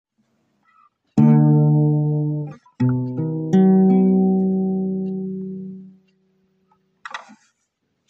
• Chromatic Clashes: Placing opposing colours (e.g., Red vs. Blue) signals a modulation.
Chromatic Clash (Modulation):